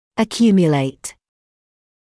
[ə’kjuːmjəleɪt][э’кйуːмйэлэйт]аккумулировать, накапливать; накапливаться, скапливаться